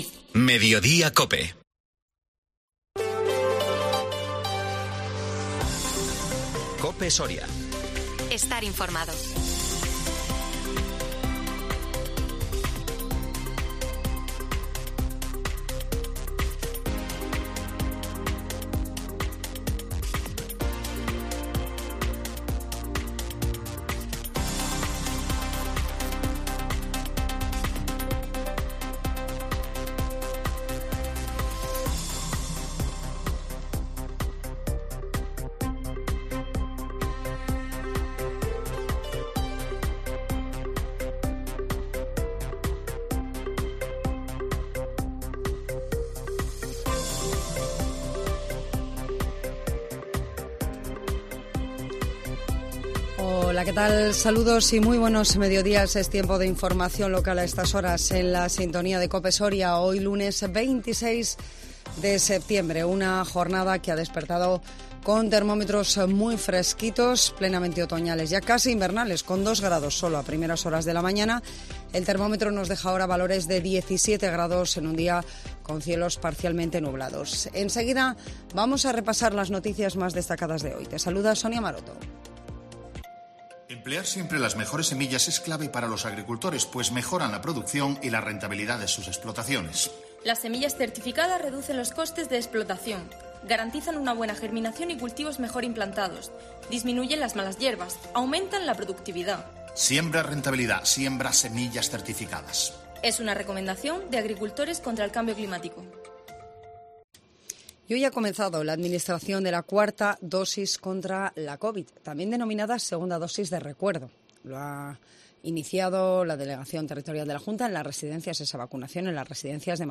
INFORMATIVO MEDIODÍA COPE SORIA 26 SEPTIEMBRE 2022